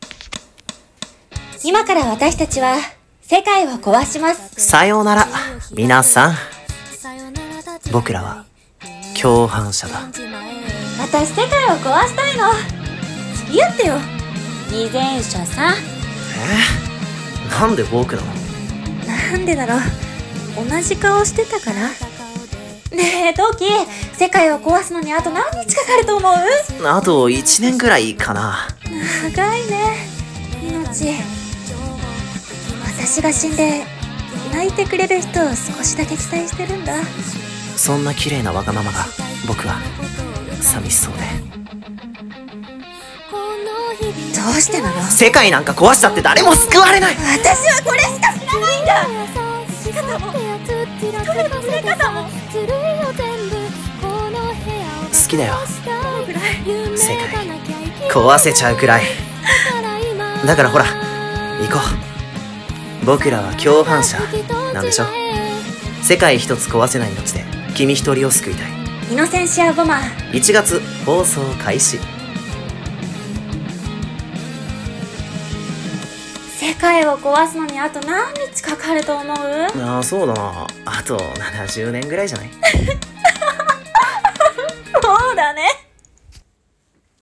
アニメCM風声劇】イノセンシア ボマー